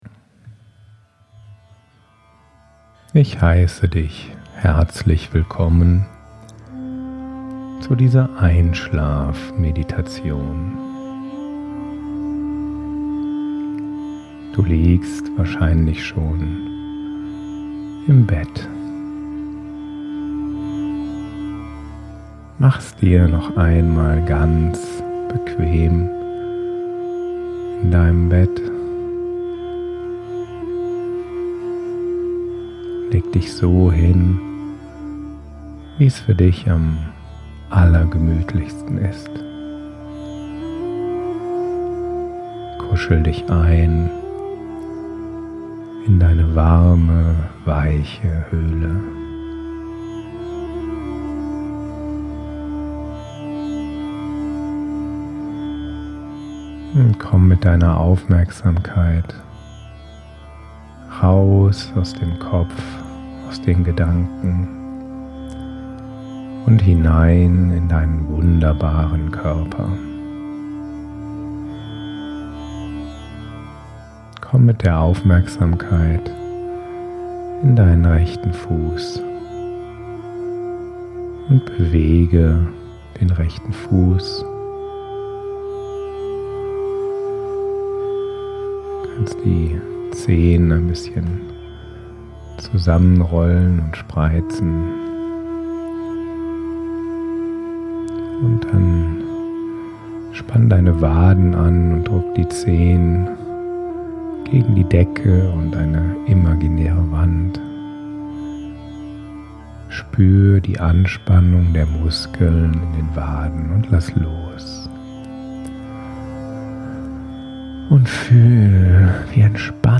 Meditation zum Einschlafen / Einschlafmeditation